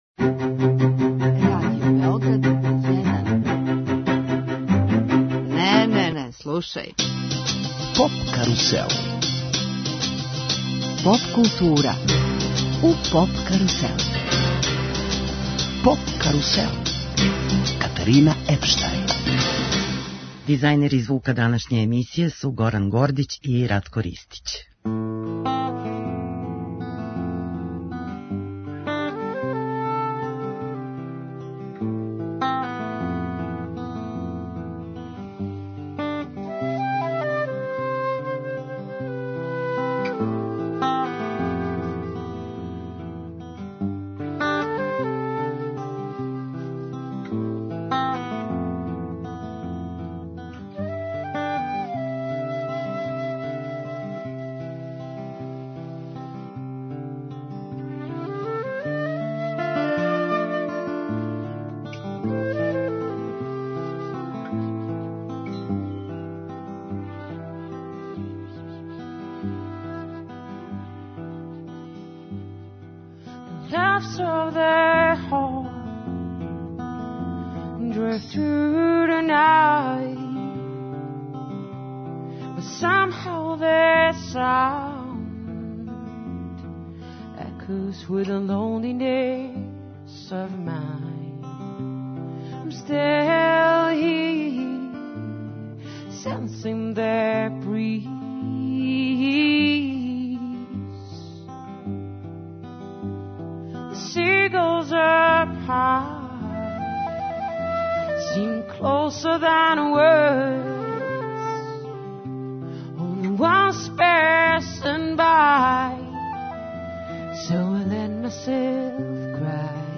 Мини концерт
Емисија је посвећена Дану Технике Радио Београда и прослави 96 година нашег медија а отвара нови циклус, у оквиру Поп карусела, мини концерата, који ће се одржавати једном недељно, у Клубу РТС - а.